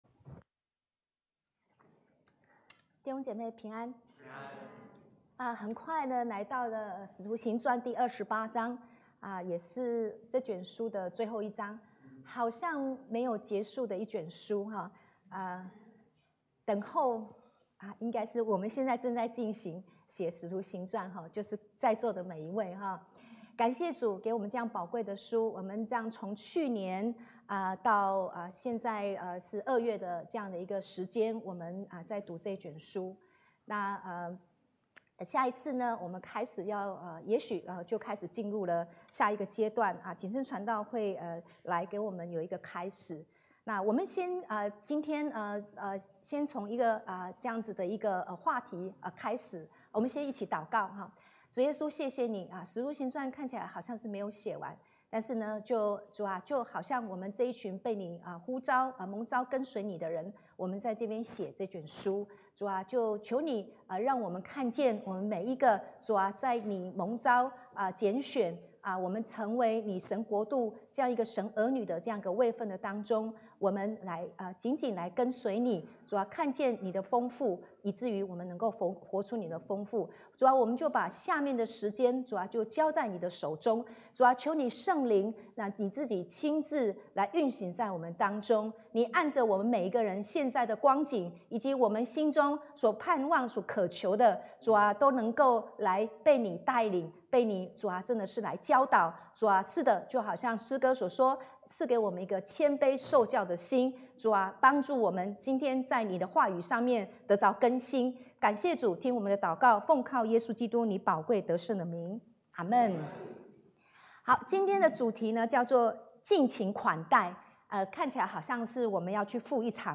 Sermons | 基督教主恩堂